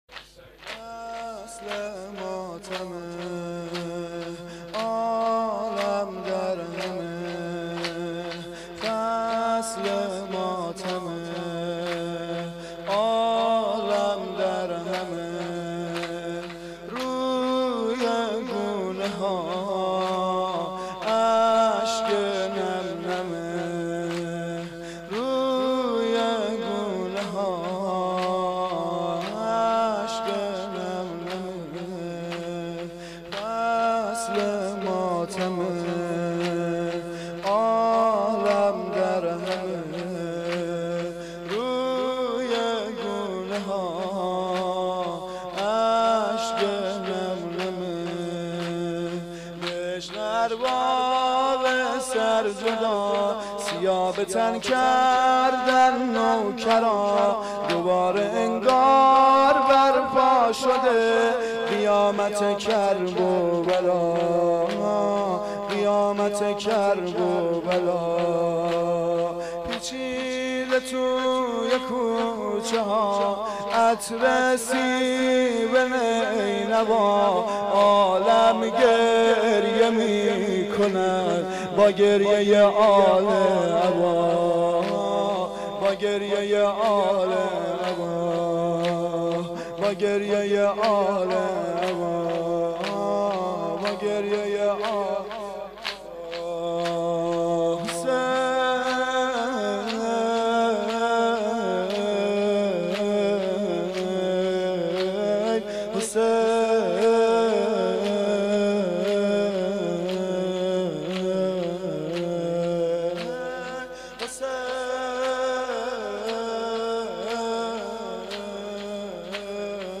زمینه شب اول محرم1391
هیئت عاشقان ثارالله کرج